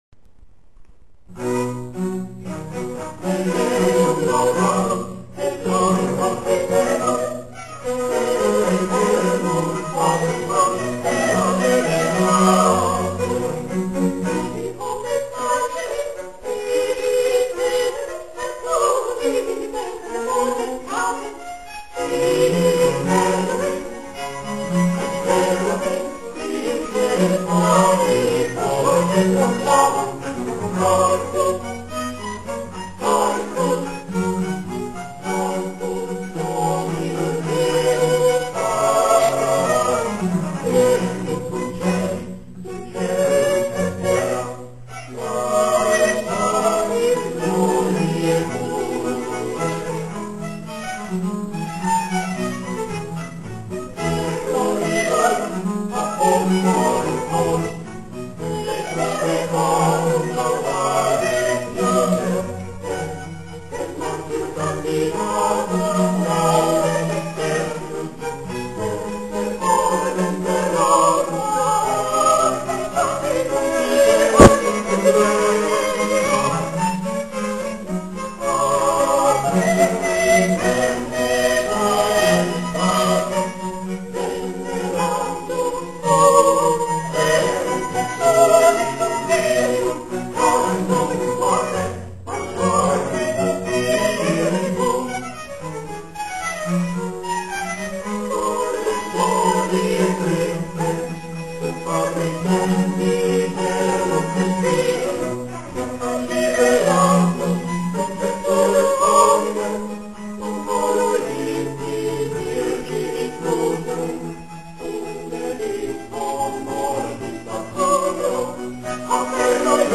25. komorní koncert na radnici v Modřicích
vokální soubor Sol et Sedes
Ukázkové amatérské nahrávky WMA: